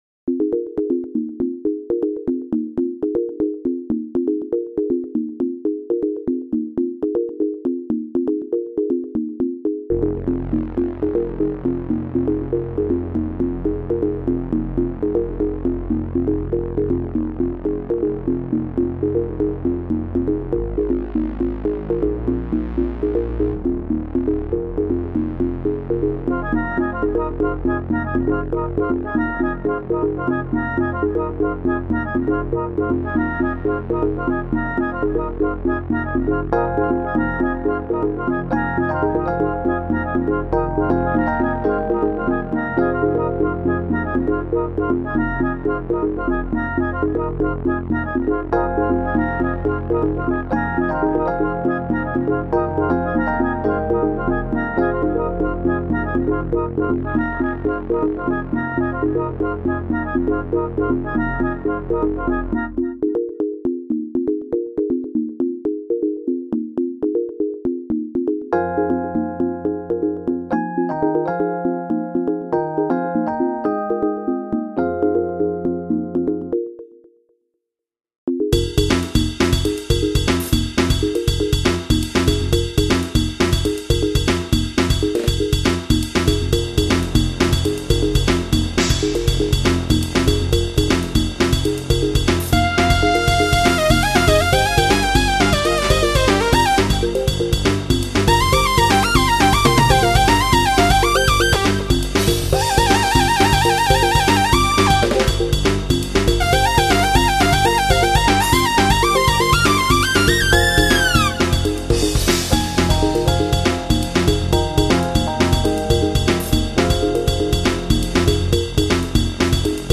The main figure is based around a repeating 11/16 | 11/16 | 10/16 form, which neatly ties up to make 8/4 for the other instruments.